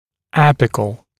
[‘æpɪkl][‘эпикл]апикальный, верхушечный